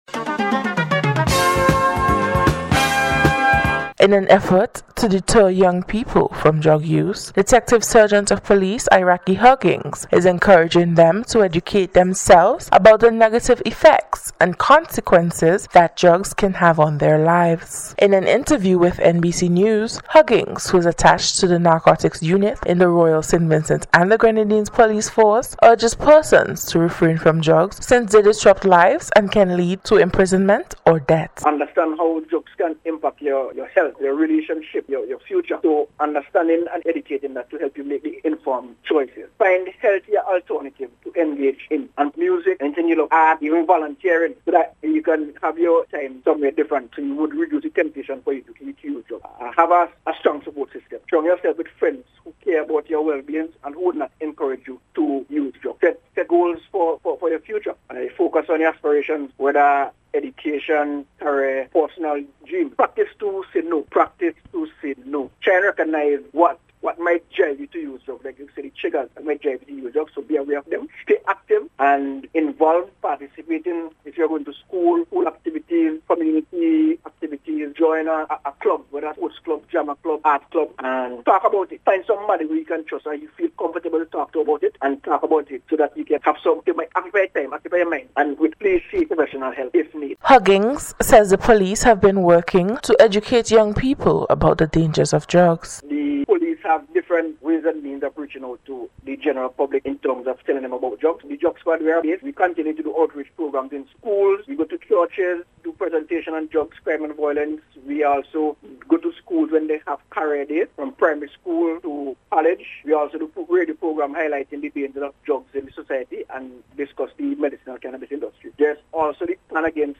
NBC’s Special Report- Friday 17th January,2025